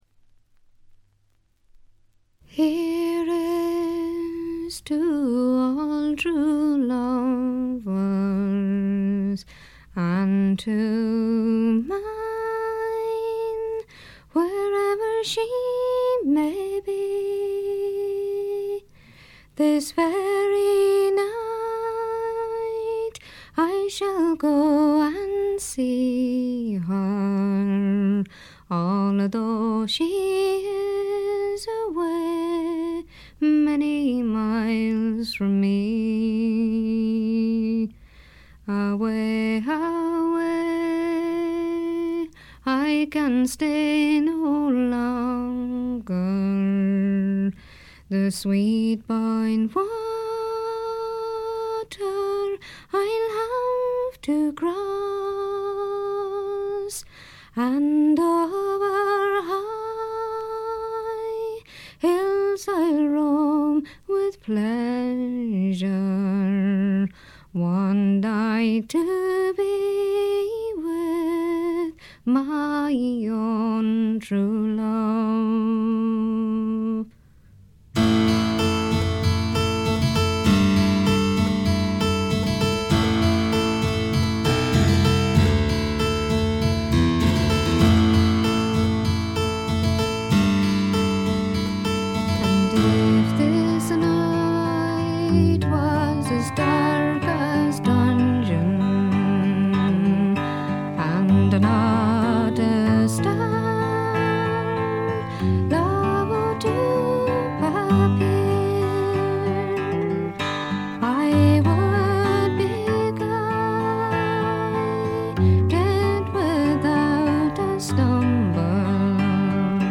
ほとんどノイズ感無し。
時に可憐で可愛らしく、時に毅然とした厳しさを見せる表情豊かで味わい深いヴォーカルがまず最高です。
試聴曲は現品からの取り込み音源です。